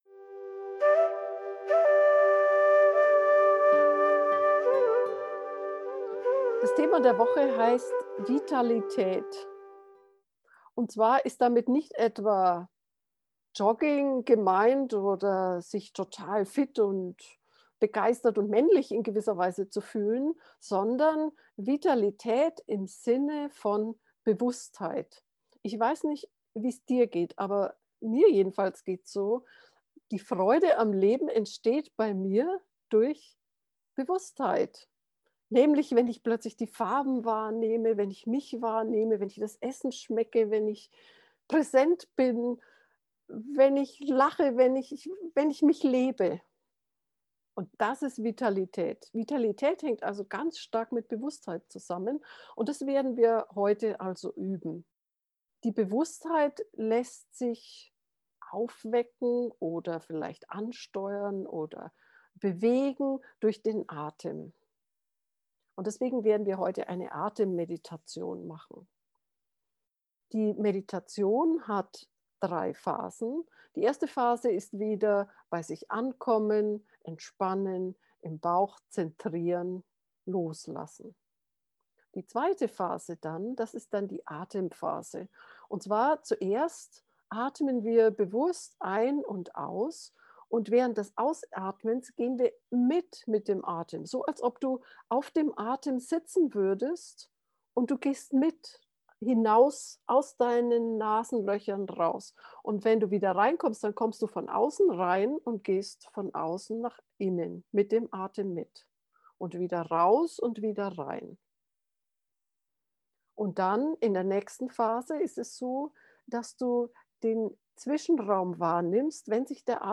In dieser geführten Atemmeditation entspannst du zunächst und dann folgst du dem Atem hinein und hinaus.
vitalitaet-bewusstheit-lebensfreude-gefuehrte-meditation